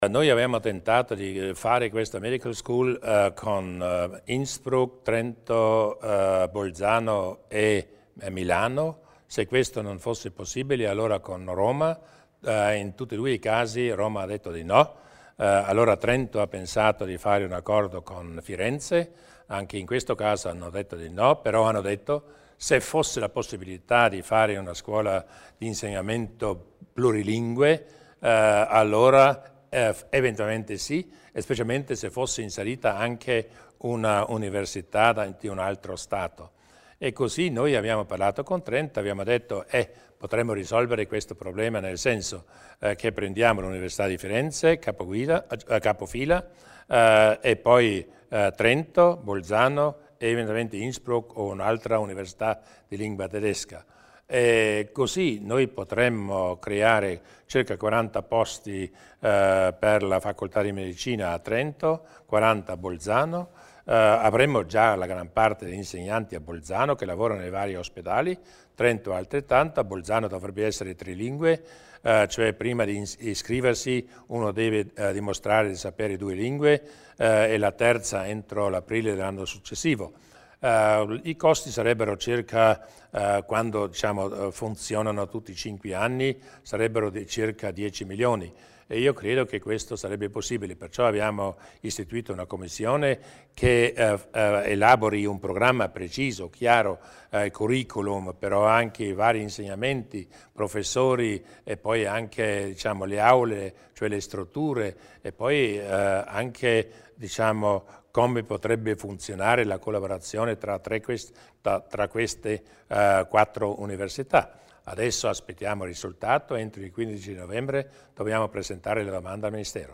Il Presidente Durnwalder illustra il progetto della Medical School